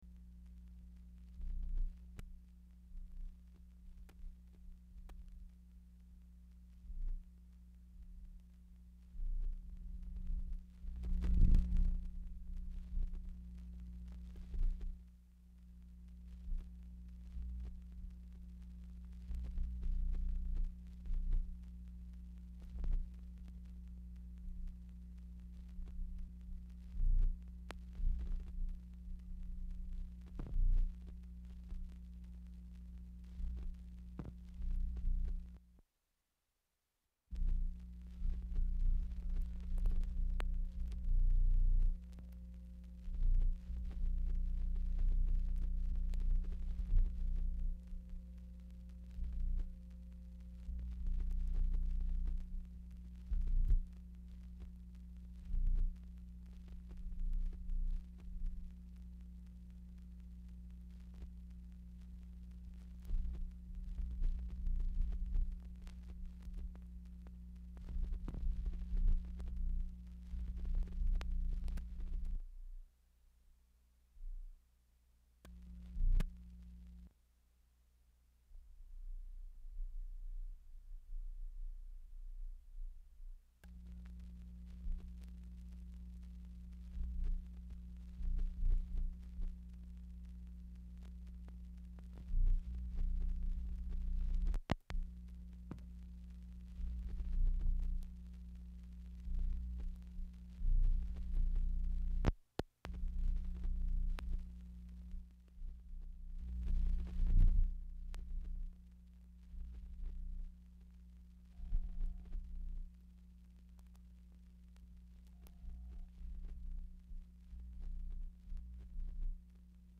Telephone conversation # 12726, sound recording, MACHINE NOISE, 2/19/1968, time unknown | Discover LBJ
RECORDED ON REEL-TO-REEL TAPE, NOT DICTABELT
Specific Item Type Telephone conversation